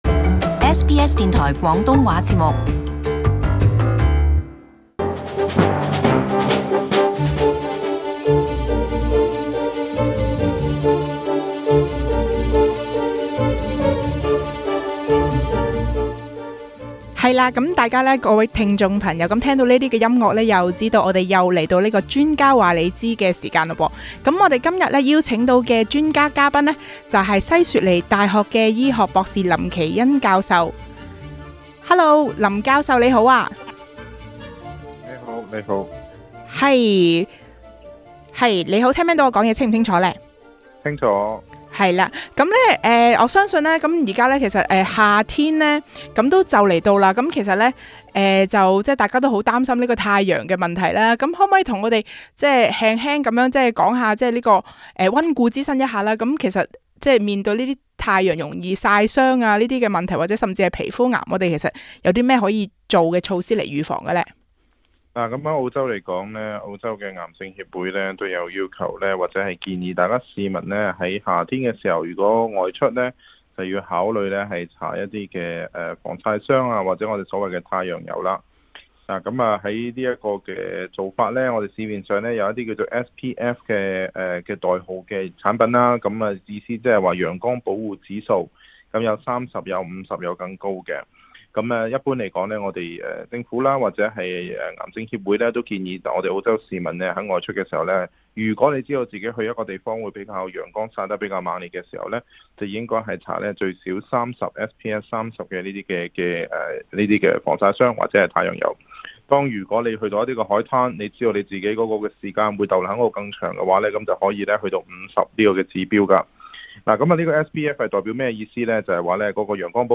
SBS广东话播客
另外他也解答多位听衆的来电。